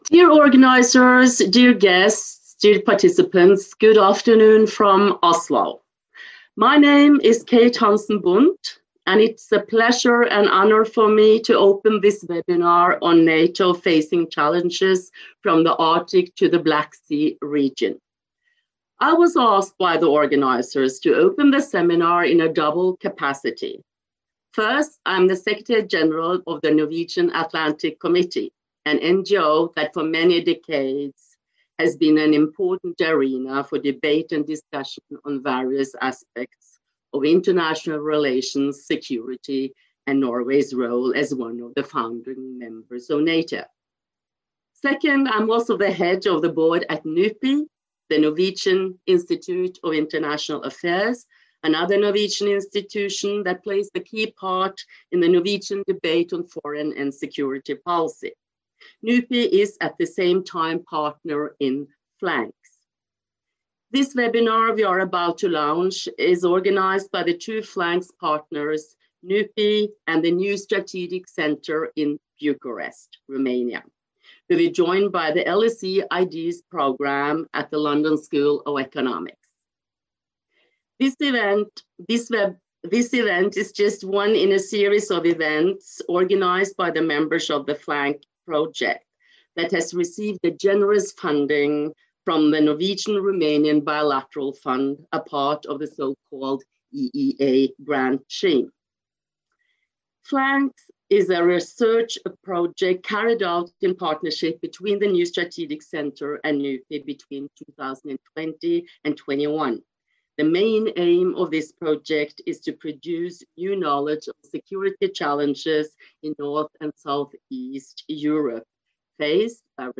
Catch up on this LSE IDEAS, NSC and NUPI conference exploring the challenges NATO faces in the Arctic and Black Sea Region.